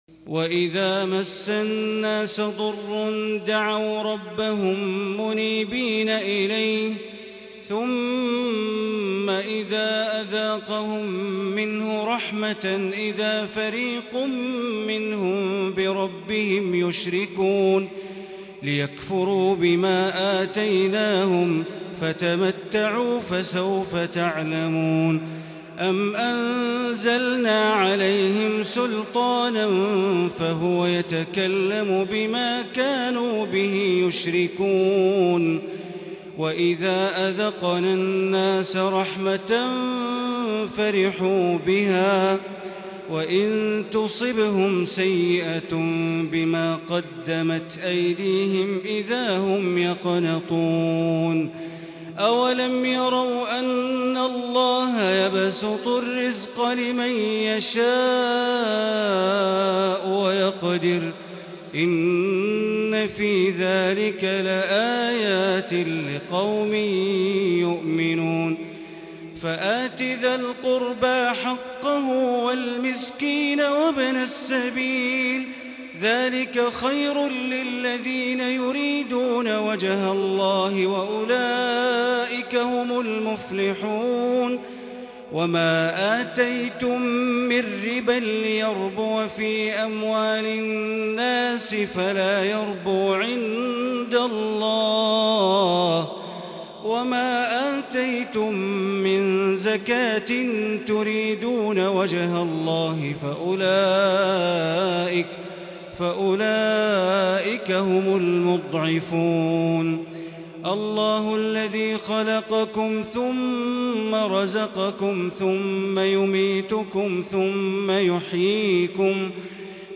(24th night of AlMasjid AlHaram Taraweh | Surah ArRum(33-60) & Luqman(1-34 > 1441 > Taraweeh - Bandar Baleela Recitations